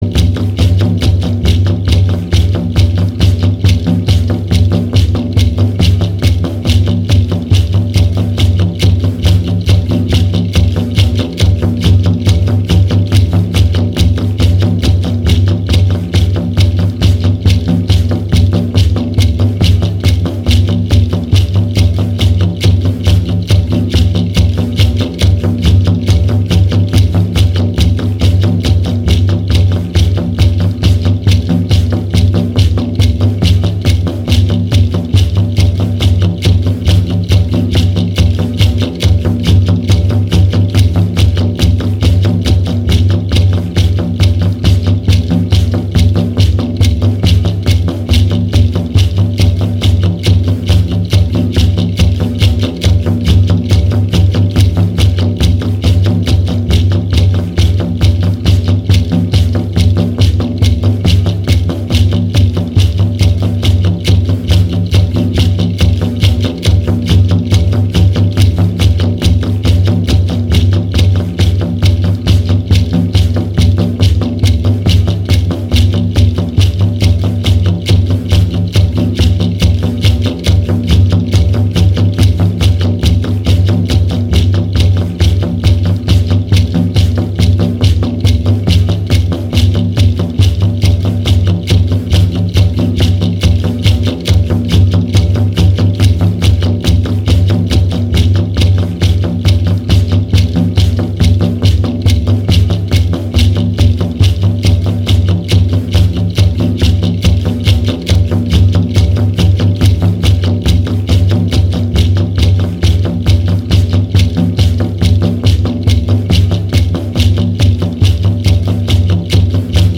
Shamanic-Journey-Drum-Rattles0.mp3